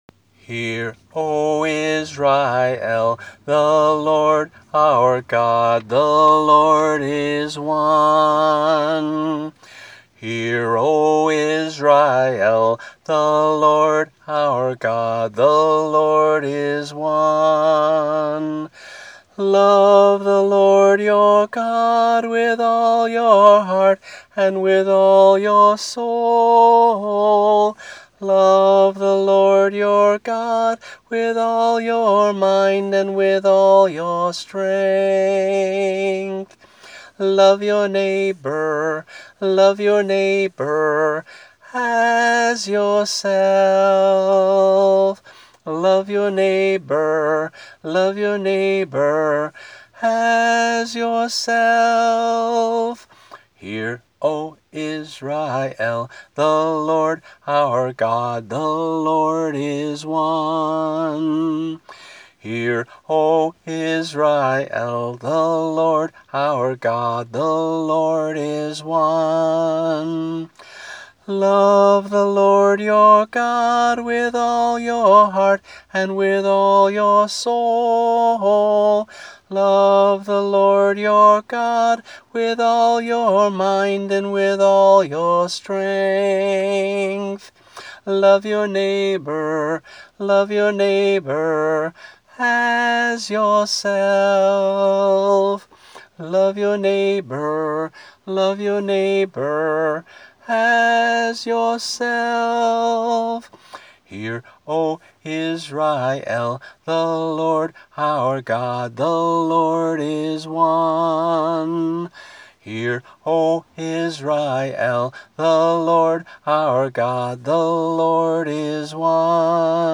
(a 3-part round)